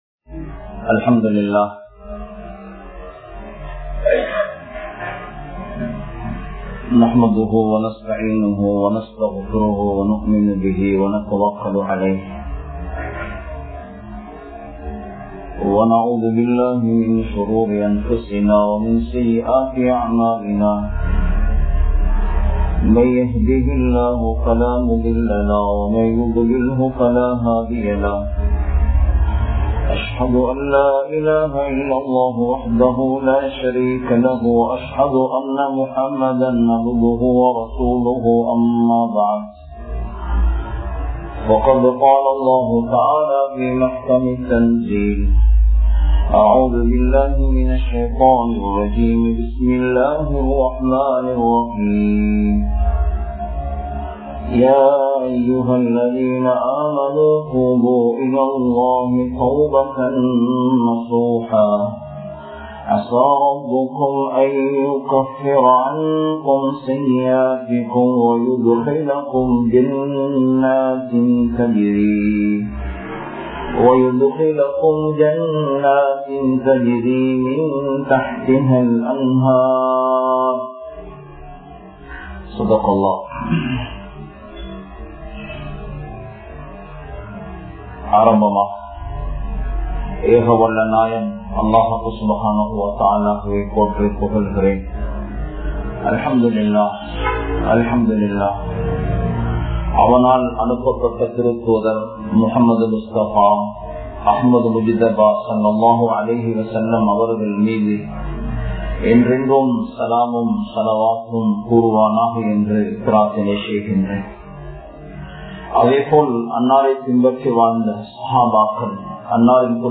Niranthara Marumaikku Thayaaraa?(நிரந்தர மறுமைக்கு தயாரா?) | Audio Bayans | All Ceylon Muslim Youth Community | Addalaichenai
Saliheen Jumua Masjidh